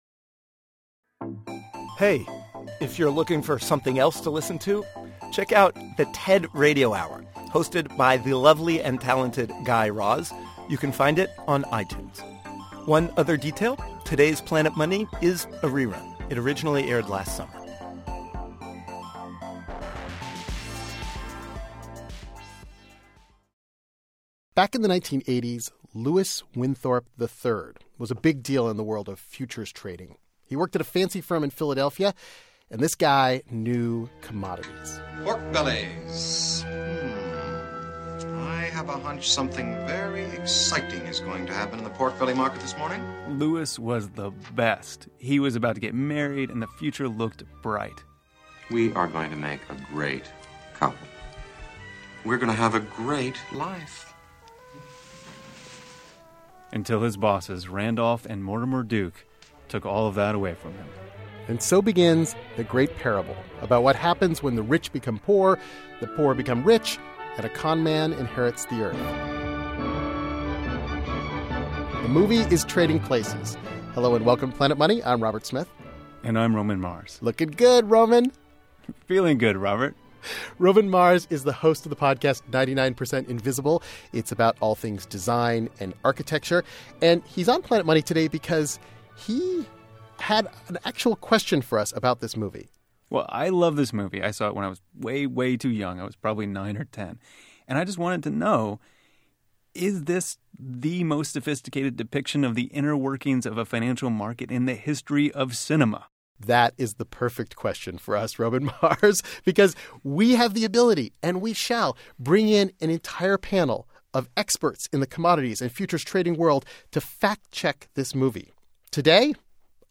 Beschreibung vor 11 Jahren Note: Today's show is a rerun. It originally ran on July 2013.On today's show, we talk to commodities traders to answer one of the most important questions in finance: What actually happens at the end of Trading Places?We know something crazy happens on the trading floor.